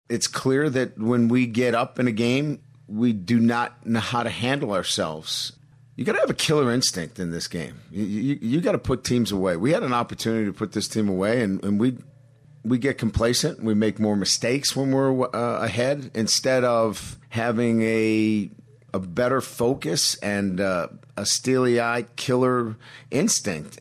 The Tigers were up by four points with just six minutes left in the fourth quarter when they allowed USC to rally, which caused Tigers coach Brian Kelly to rant about his team not being able to finish games: